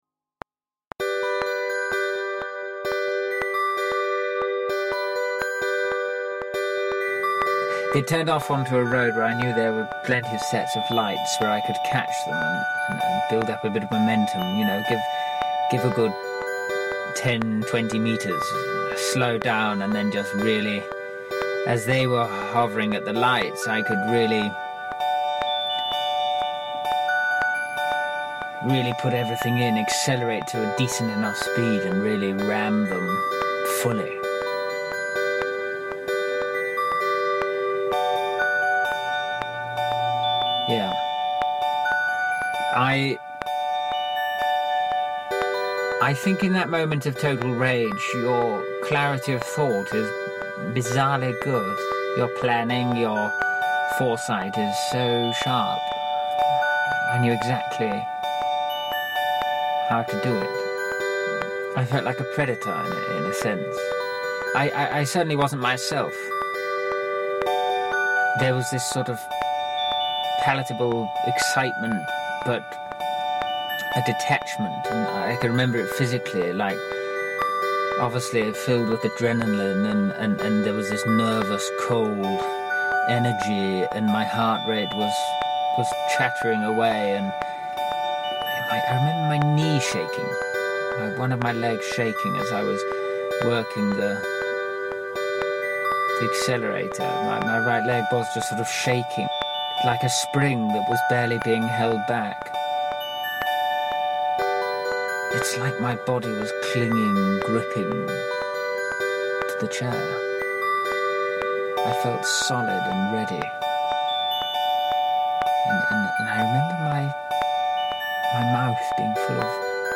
A journey with all sorts of characters you can choose to listen to or just enjot the atmosphere and space they create
Genres: Arts, Comedy, Improv, Performing Arts